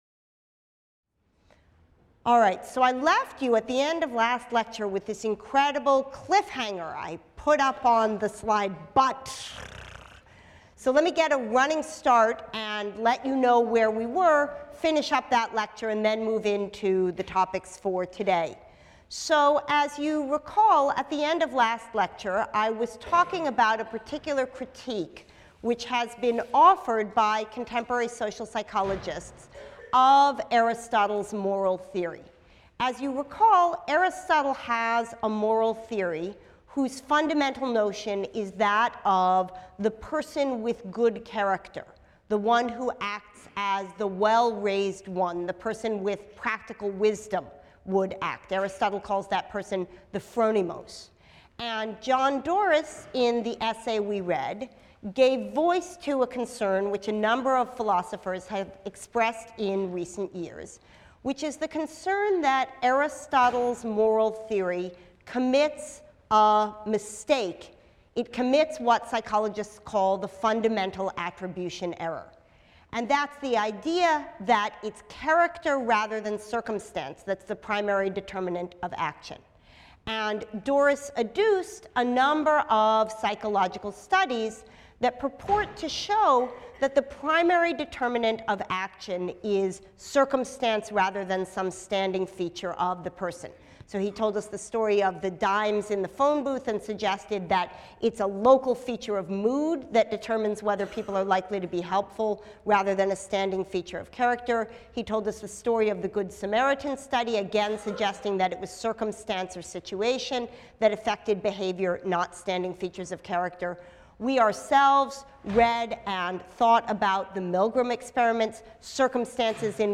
PHIL 181 - Lecture 11 - Weakness of the Will and Procrastination | Open Yale Courses